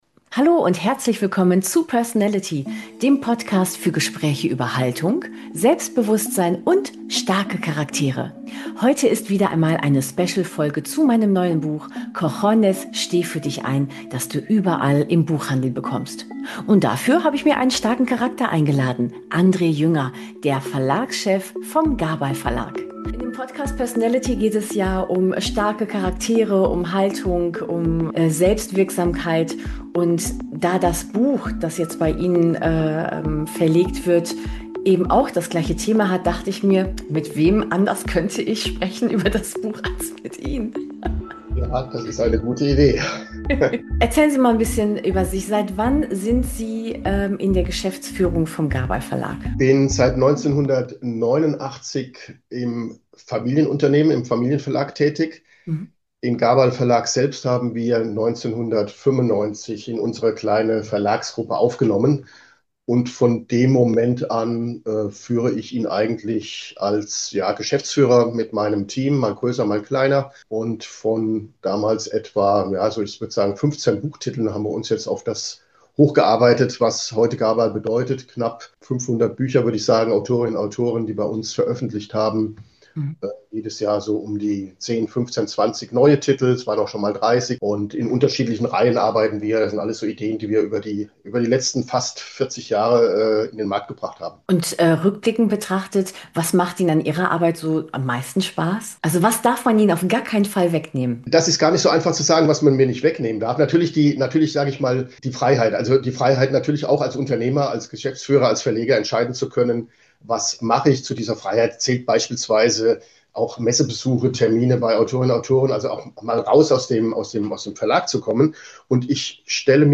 Ein ehrliches, reflektiertes Gespräch über Freiheit im Unternehmertum, langfristiges Denken und die stille Kraft guter Inhalte.